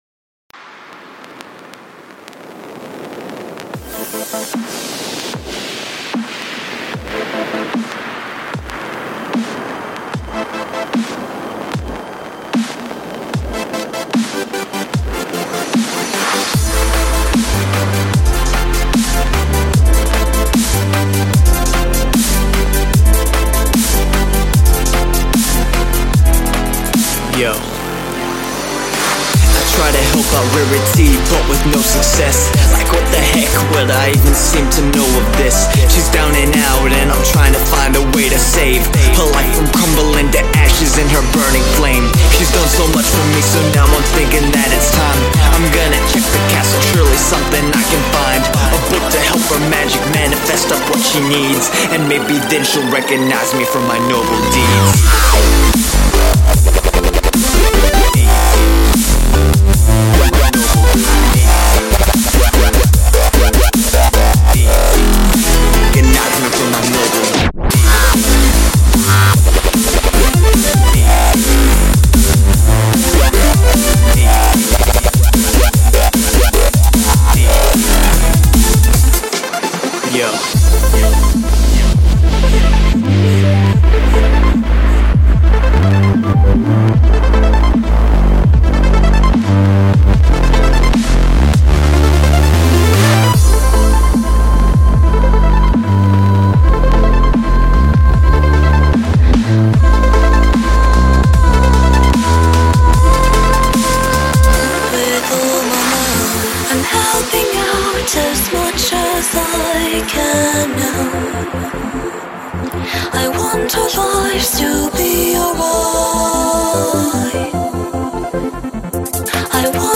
rapp-performance
beautiful voice